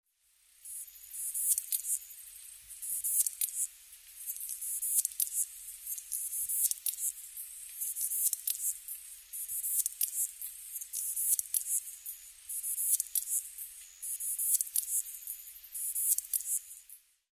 エゾツユムシ　Ducetia chinensisキリギリス科
日光市稲荷川中流　alt=730m  HiFi --------------
Rec.: SONY TC-D5M
Mic.: audio-technica AT822
他の自然音：　 ヤマヤブキリ